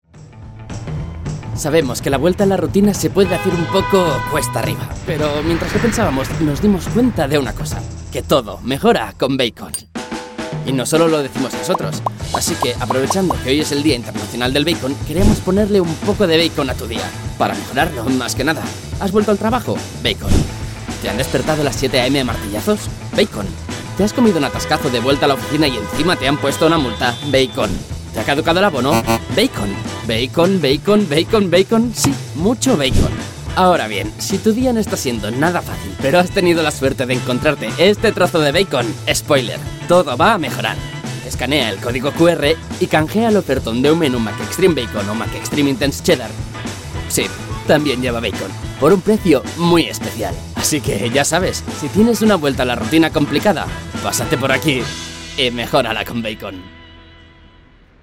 sehr variabel
Jung (18-30)
Commercial (Werbung)